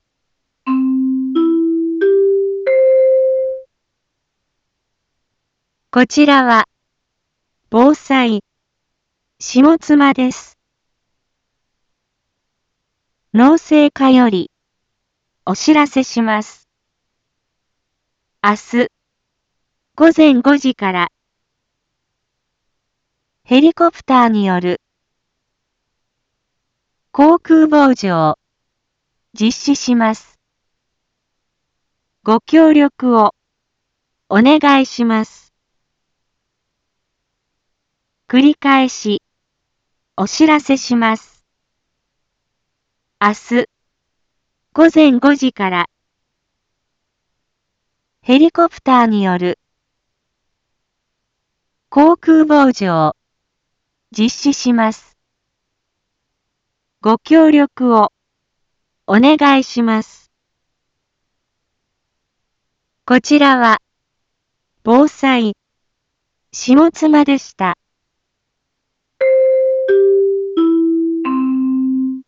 一般放送情報
Back Home 一般放送情報 音声放送 再生 一般放送情報 登録日時：2022-07-23 18:01:13 タイトル：農林航空防除について インフォメーション：こちらは、防災、下妻です。